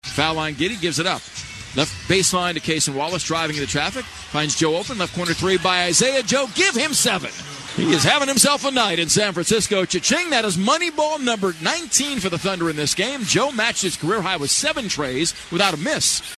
PBP Thunder 118-97-Joe Three-Point Shot.MP3